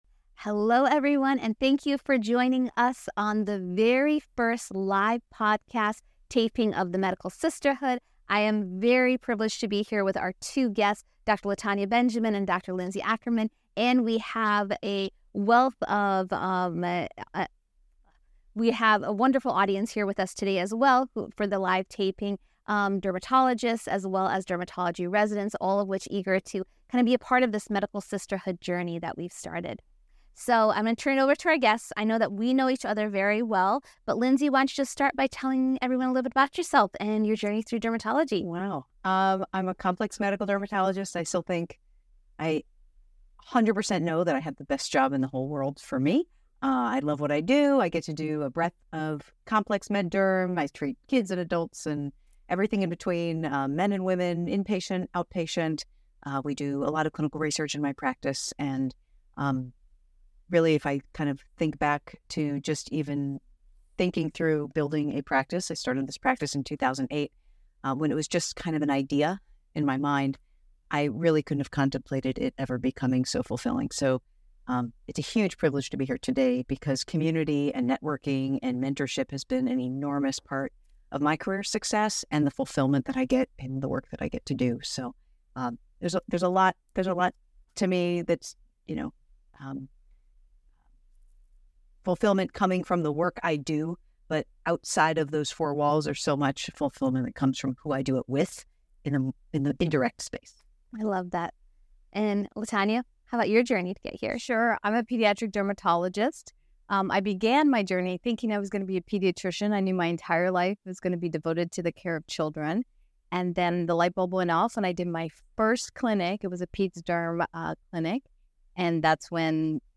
recorded live at the American Academy of Dermatology (AAD) 2025 Annual Meeting